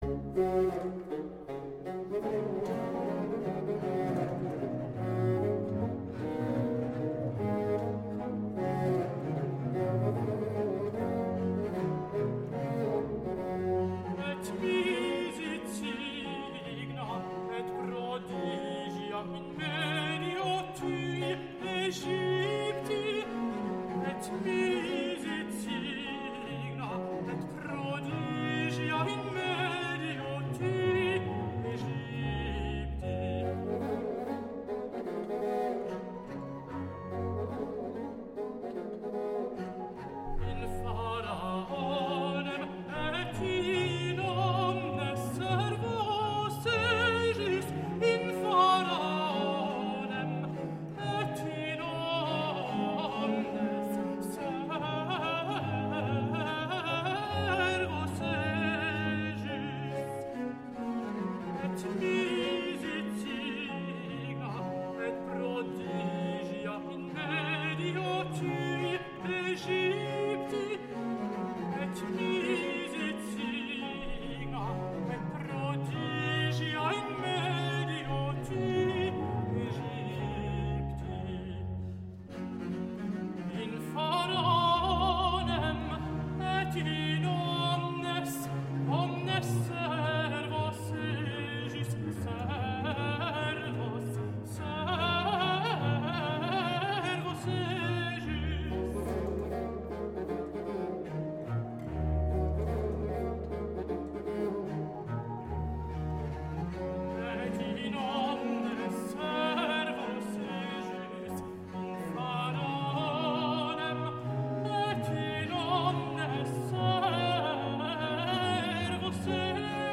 Grand motet
Recit de Haute-Contre